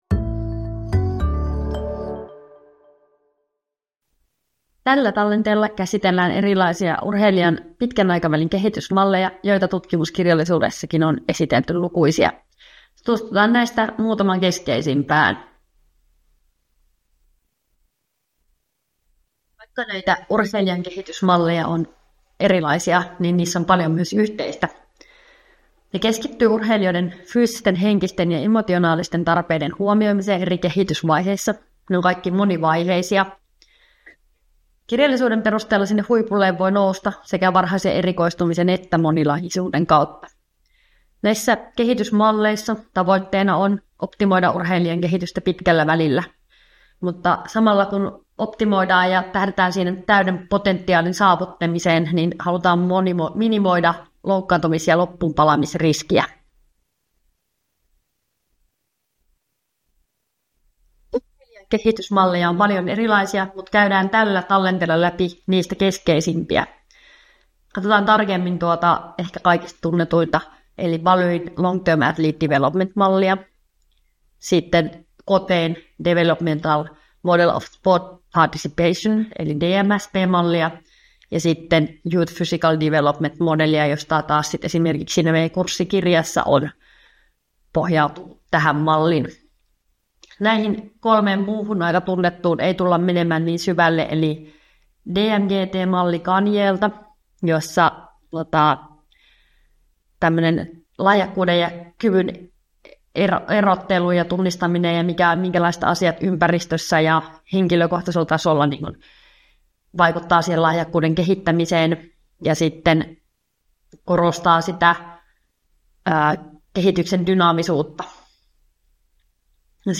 luento kehitysmalleista